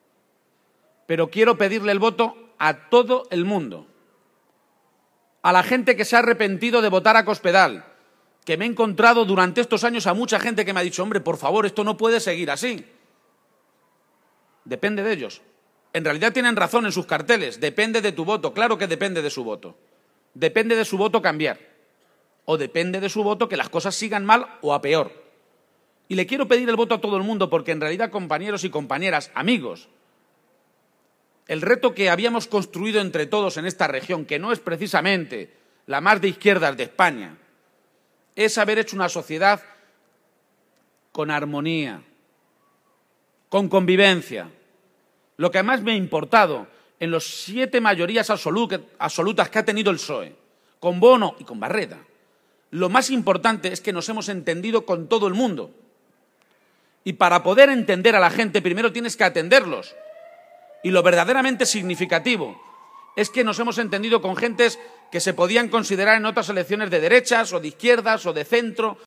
El candidato socialista a la Presidencia de Castilla-La Mancha protagonizo el mitin de fin de campaña en Albacete y anuncio que promoverá un código ético público que obligue a los partidos a presentar programa